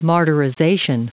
Prononciation du mot martyrization en anglais (fichier audio)